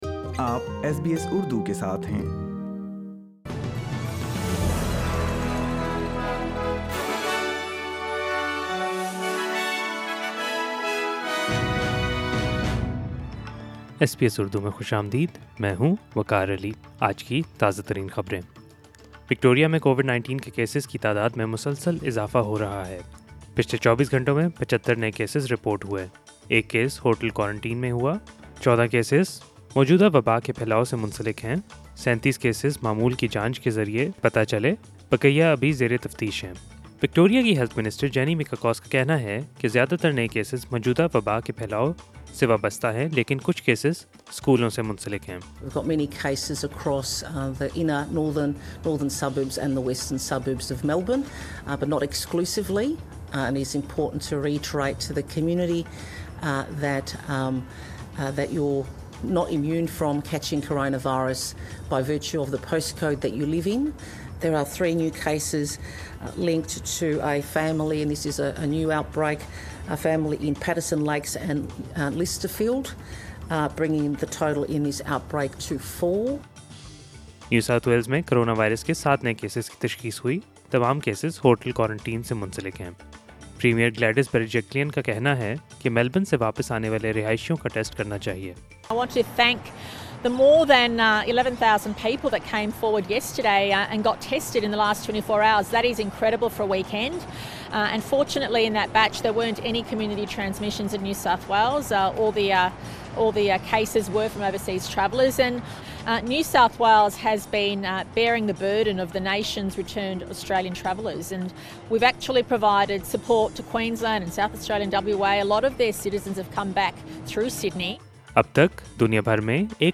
SBS Urdu News 29 June 2020